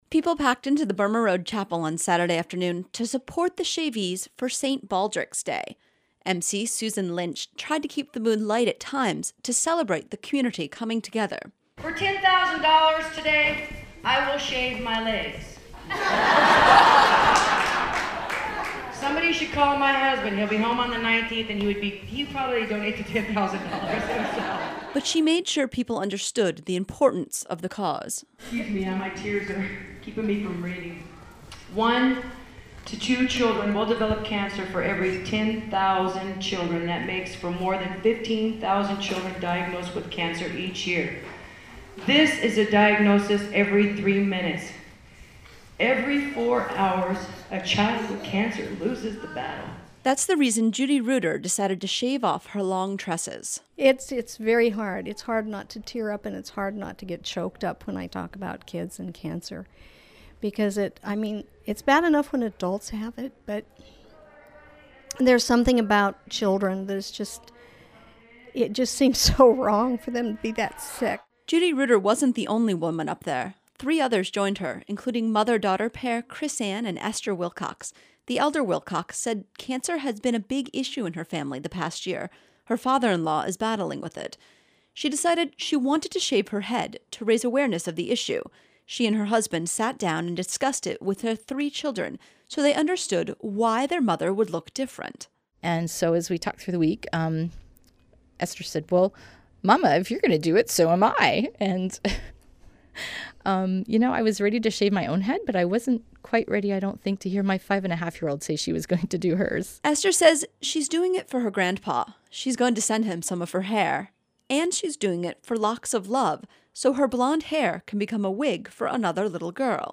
Unalaska, AK – Unalaska hosted it's first ever St. Baldrick's Day event in the Burma Road Chapel this weekend. Thirty-four community members shaved their heads to raise awareness of cancer and raise money to fund childhood cancer research.